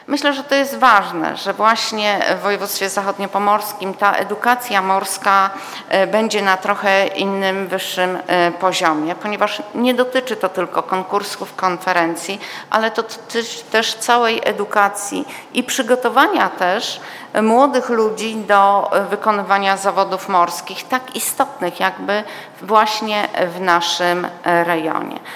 Dotyczy to przygotowania młodych ludzi do wykonywania zawodów morskich – wyjaśnia Katarzyna Koszewska, Zachodniopomorska Kuratorka Oświaty